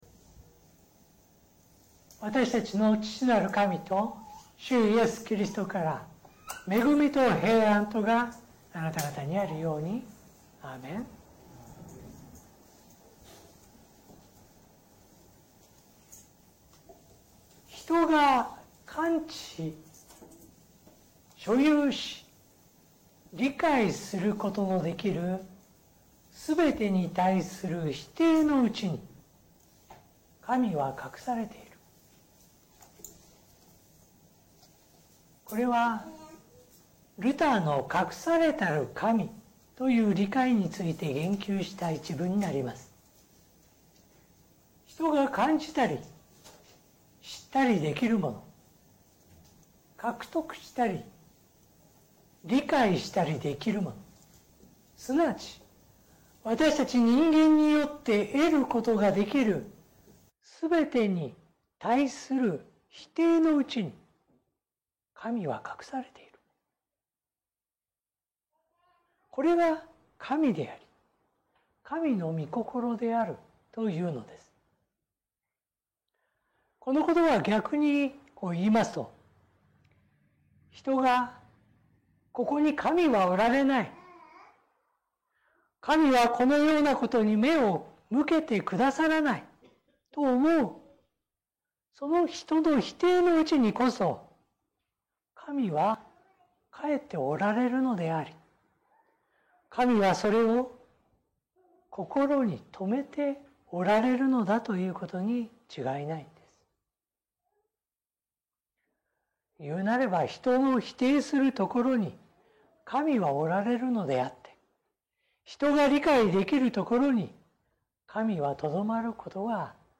説教音声